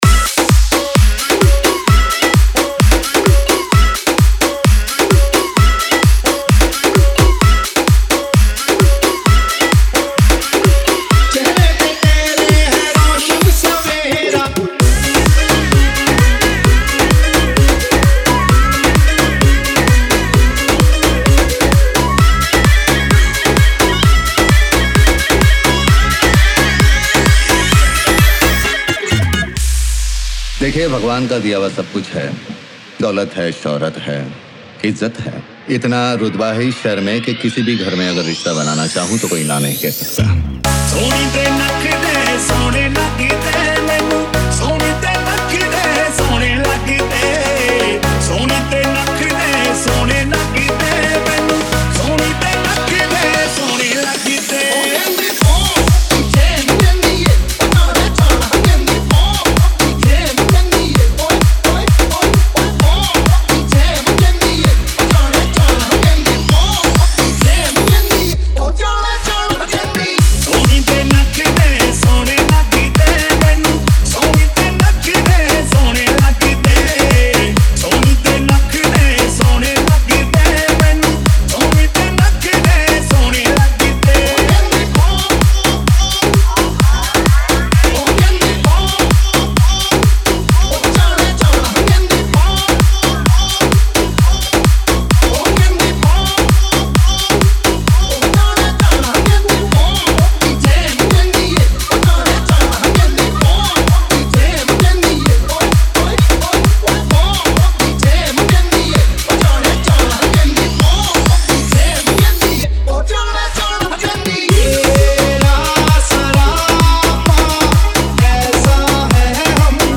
edm mashup dj music